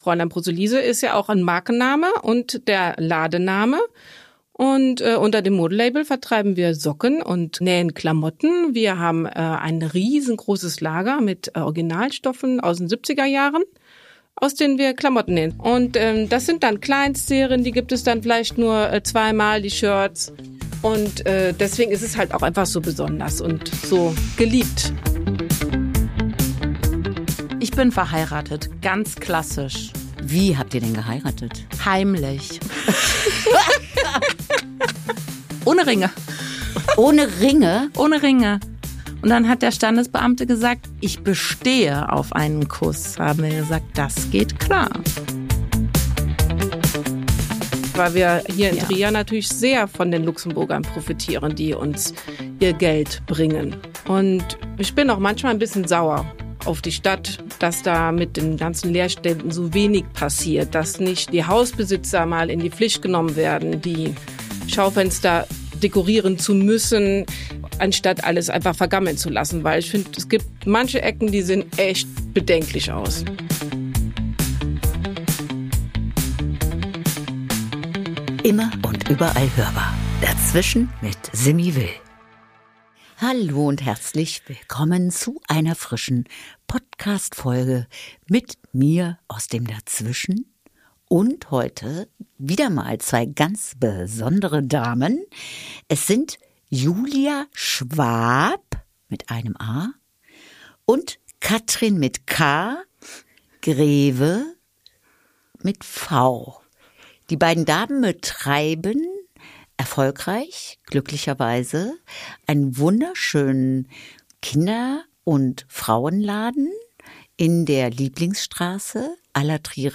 Ein Gespräch wie selbstgenäht: ehrlich, laut, liebevoll, politisch – ein sweetes Durcheinander mit einer Handvoll Glitzer.
Und ganz viel Lachen.